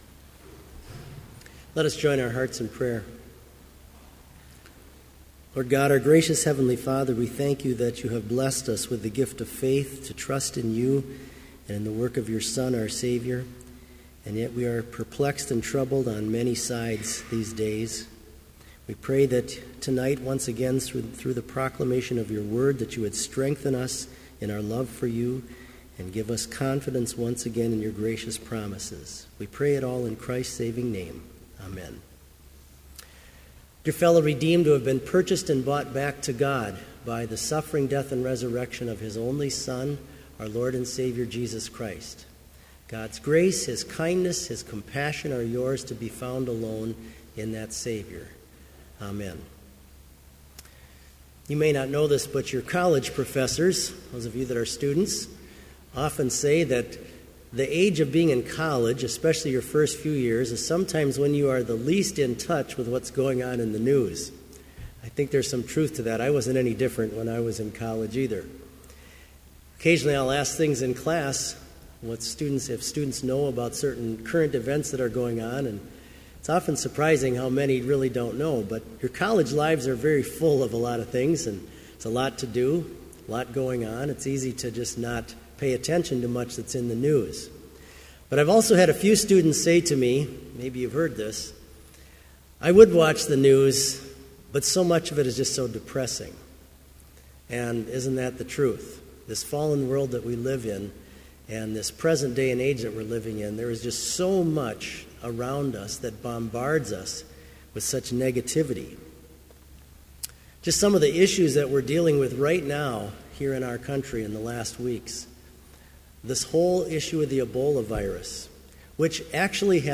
Vespers service on October 8, 2014, at Bethany Chapel in Mankato, MN, (audio available)
Complete service audio for Evening Vespers - October 8, 2014